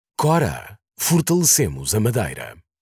Male
Adult (30-50)
Radio / TV Imaging
Words that describe my voice are Portuguese Voice Over, Strong, Warm.